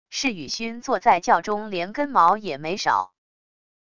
释羽薰坐在轿中连根毛也没少wav音频生成系统WAV Audio Player